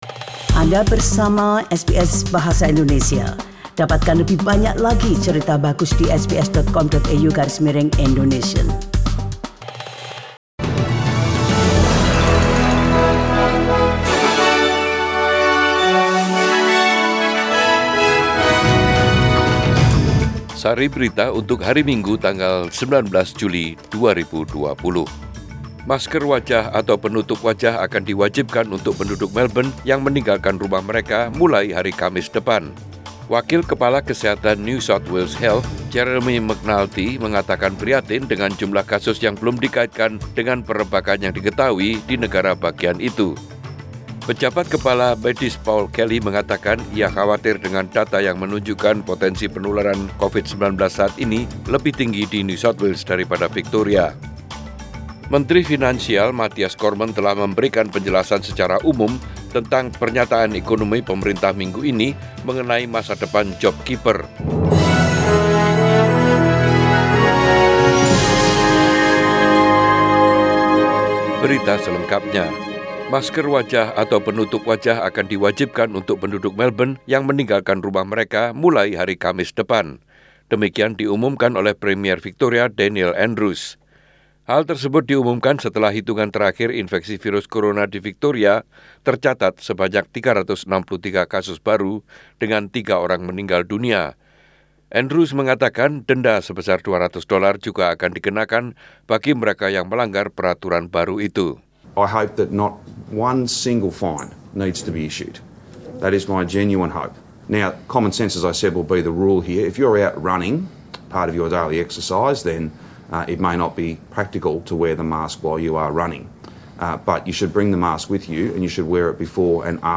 SBS Radio News in Bahasa Indonesia - 19 July 2020